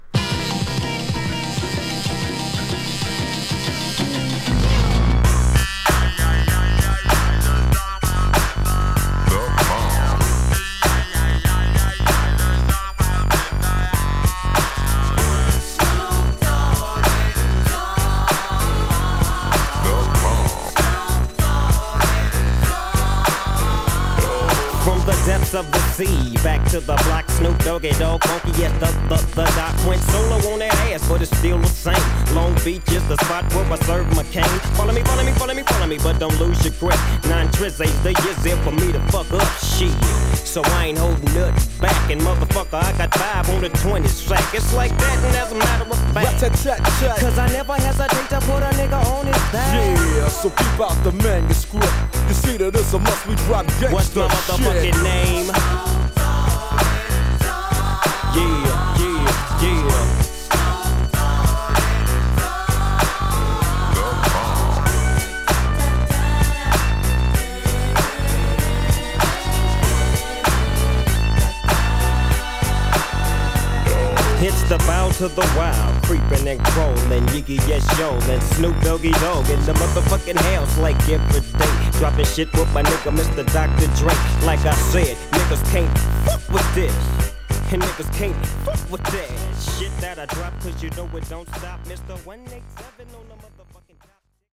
正に究極のＧファンク